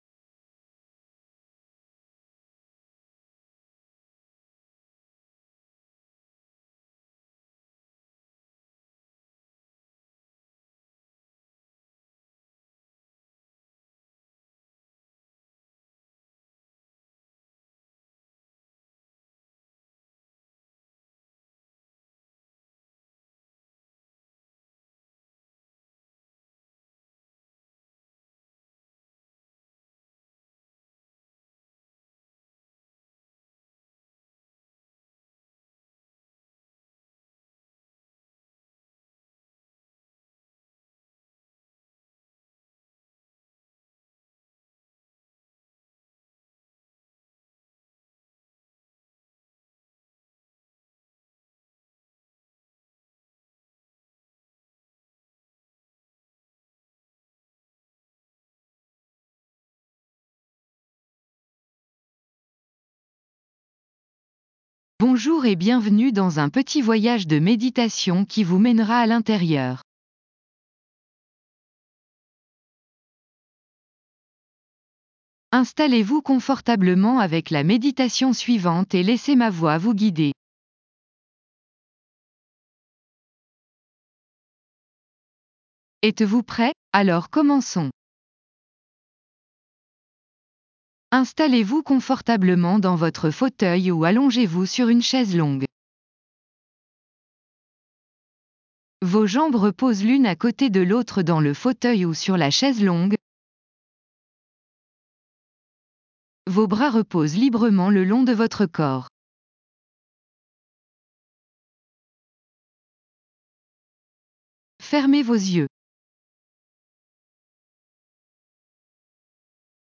Restez concentré sur ces passages pendant que vous laissez la musique vous submerger.